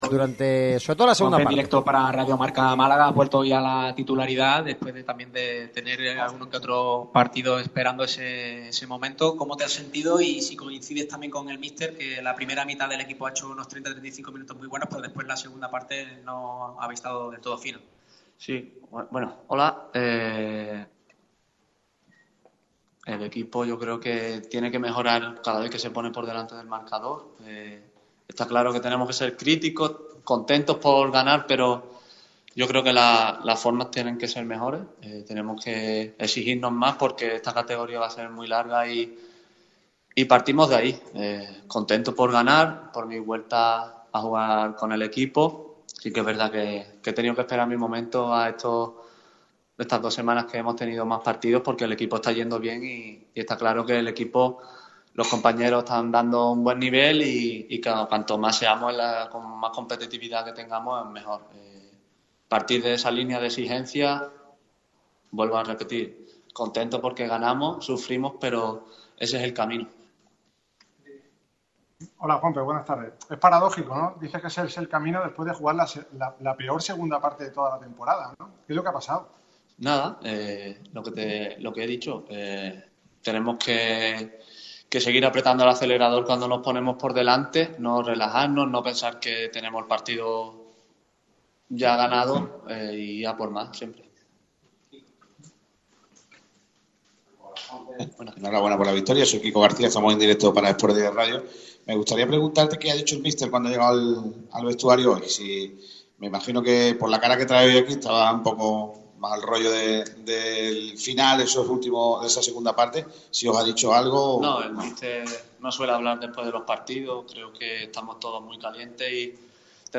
El centrocampista gaditano hoy regresaba a la alineación titular de Sergio Pellicer para medirse a la UD Melilla y ha comparecido ante los medios en la sala de prensa de La Rosaleda tras la victoria.